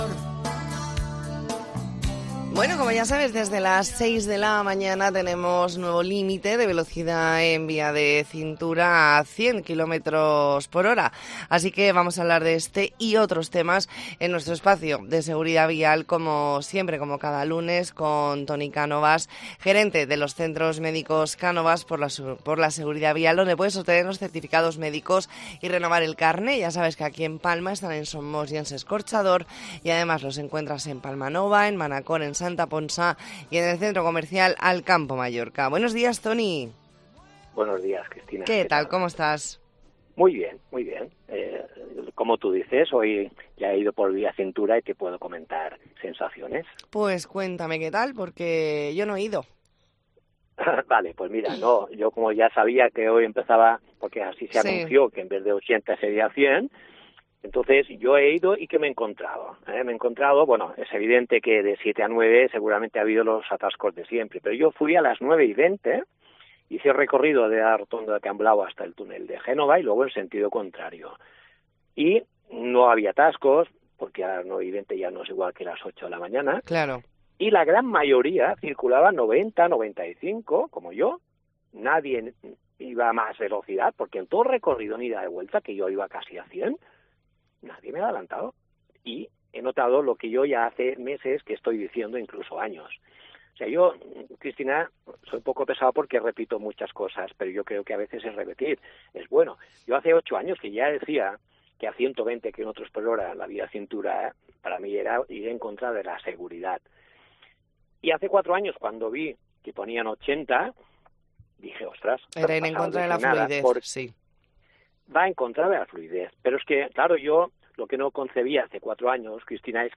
Entrevista en La Mañana en COPE Más Mallorca, lunes 18 de diciembre de 2023.